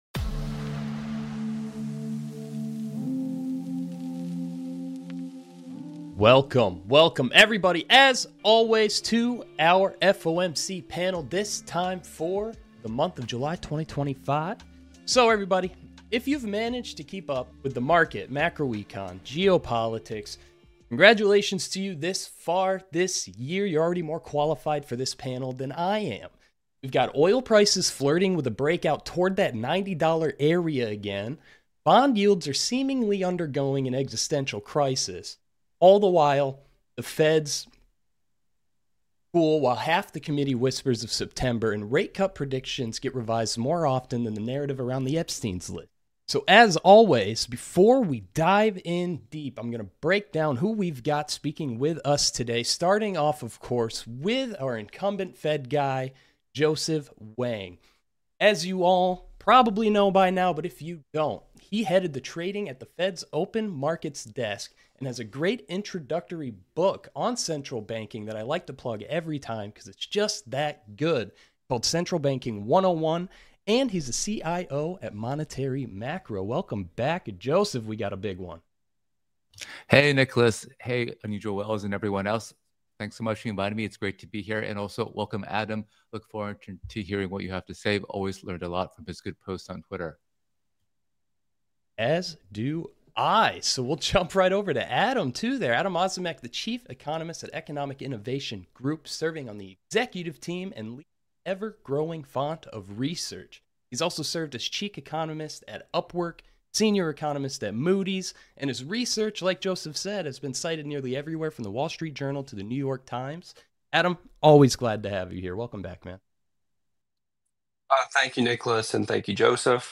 1 Unusual Whales Pod Ep. 63: FOMC Rates Unchanged, Labor Markets, and Geopolitics 1:27:37 Play Pause 2h ago 1:27:37 Play Pause Play later Play later Lists Like Liked 1:27:37 This episode of the Unusual Whales Pod was recorded Live on July 30th, 2025.